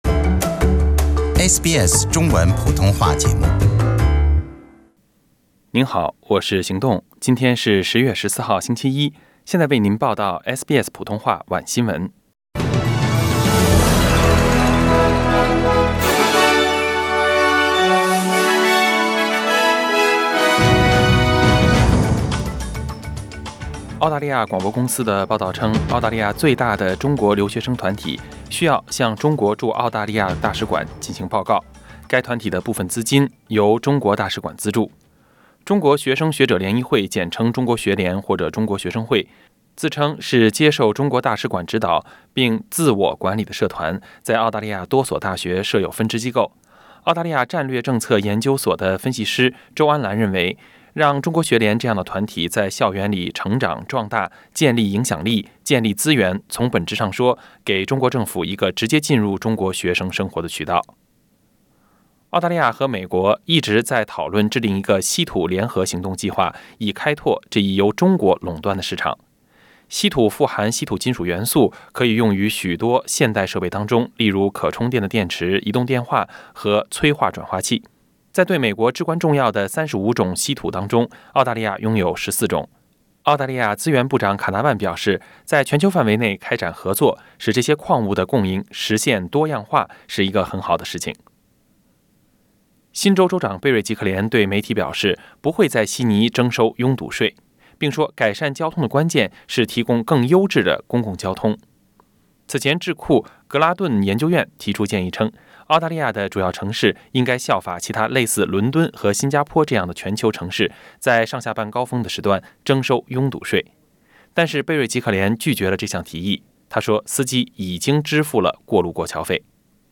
SBS晚新闻 （10月14日）